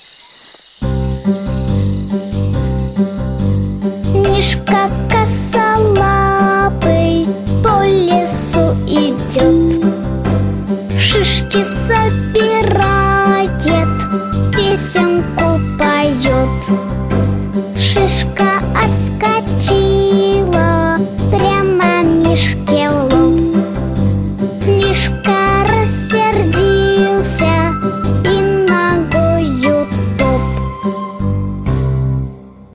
Мишка косолапый - песенка с движениями - слушать онлайн